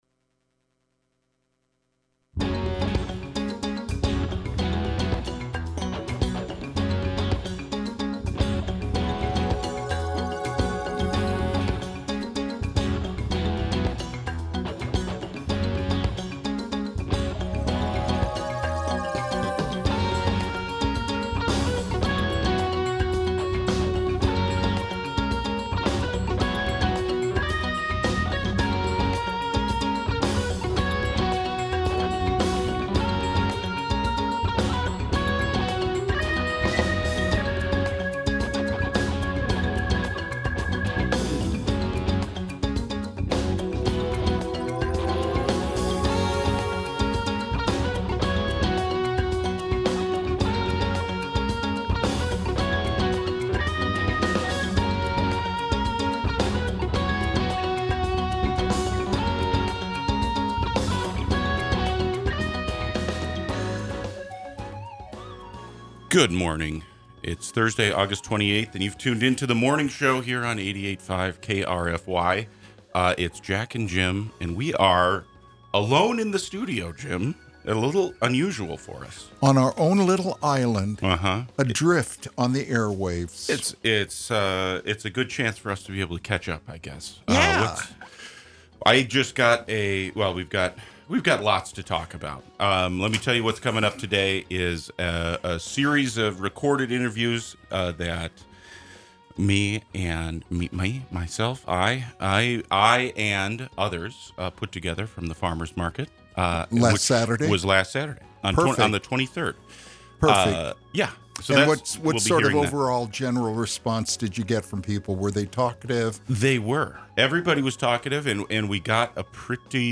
August 28, 2025: On the Scene at the Farmer’s Market